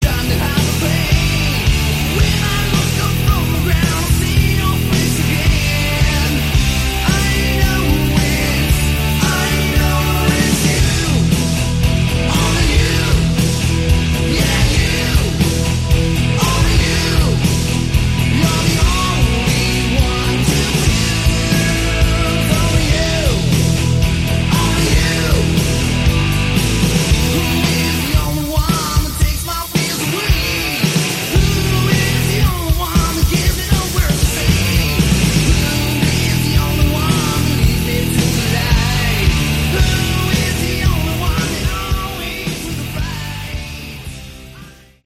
Category: Hard Rock
Vocals, Guitars, Keyboards, Piano